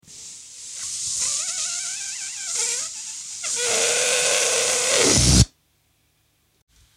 دانلود آهنگ باد 77 از افکت صوتی طبیعت و محیط
جلوه های صوتی
دانلود صدای باد 77 از ساعد نیوز با لینک مستقیم و کیفیت بالا